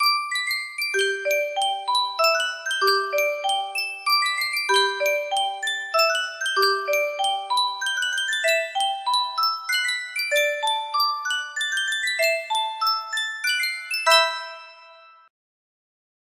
Sankyo Music Box - Rhapsody on a Theme of Paganini P7 music box melody
Full range 60